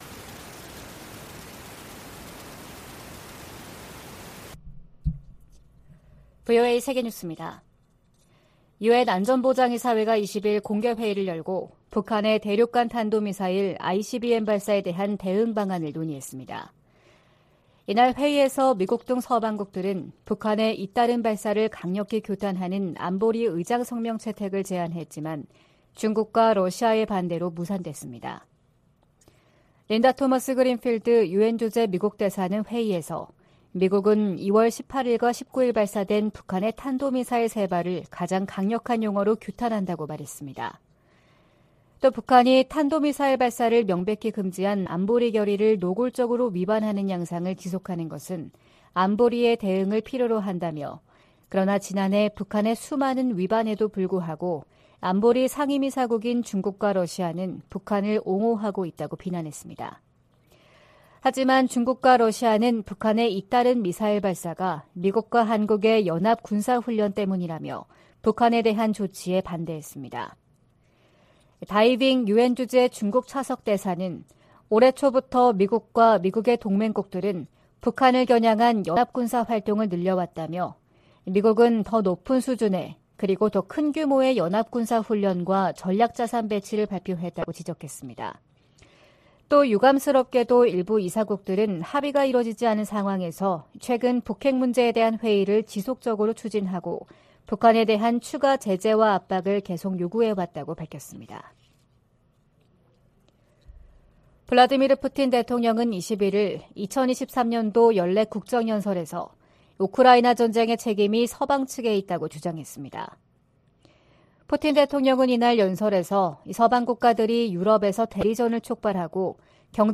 VOA 한국어 '출발 뉴스 쇼', 2023년 2월 22일 방송입니다. 유엔 안전보장이사회가 북한의 대륙간탄도미사일(ICBM) 발사에 대응한 공개회의를 개최한 가운데 미국은 의장성명을 다시 추진하겠다고 밝혔습니다. 김여정 북한 노동당 부부장은 ‘화성-15형’의 기술적 문제점을 지적하는 한국 측 분석들을 조목조목 비난하면서 민감한 반응을 보였습니다. 북한이 ICBM급에도 대기권 재진입 기술을 확보했다는 관측이 확대되고 있습니다.